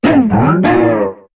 Cri de Miasmax dans Pokémon HOME.